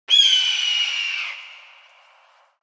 دانلود آهنگ کوه 2 از افکت صوتی طبیعت و محیط
دانلود صدای کوه 2 از ساعد نیوز با لینک مستقیم و کیفیت بالا
جلوه های صوتی